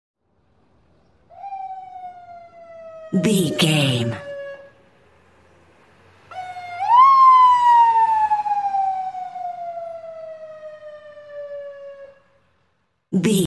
Ambulance Ext Passby Arrive Siren 77
Sound Effects
urban
chaotic
emergency